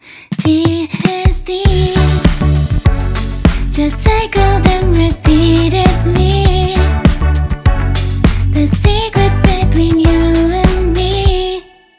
さてそれでは、ここから怒涛の視聴タイムです!! 4コードのループで、TDSの違いを感じてみましょう。今回は単一のメロディを使い回して、さまざまなコード進行をあてていくことにします。
同じ機能順行でも、頭に2回連続でT機能のコードを置くとまた緊張-弛緩の流れ方を変えることができます。
ループの繋ぎ目である4番目のコードをD機能にすると、次のループへと突入する際に勢いがつけられる良さがあります。